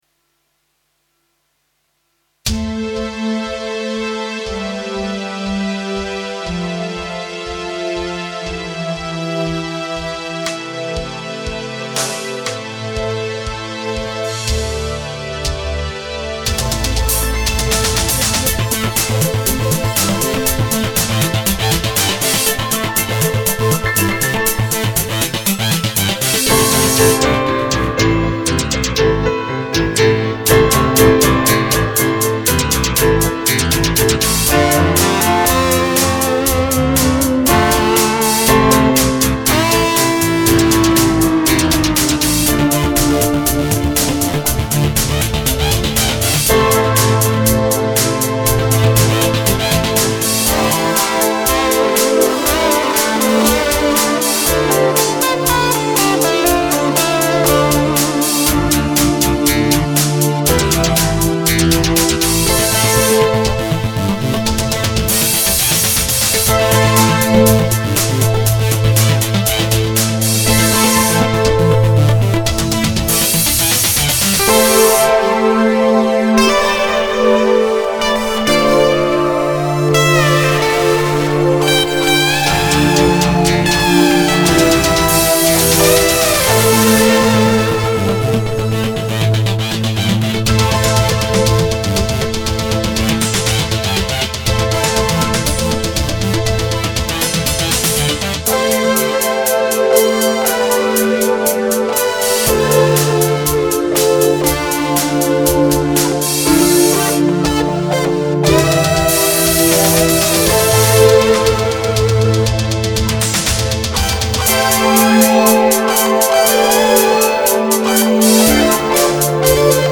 Studio Hochfilzen
Hier mit der selbst gebauten Fender Stratocaster Nachbau Gitarre.